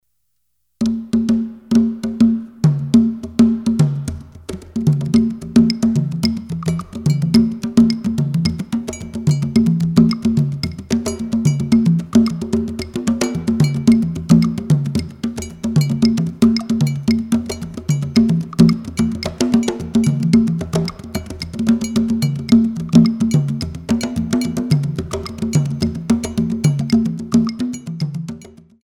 Based on traditional kpaniologo rhythm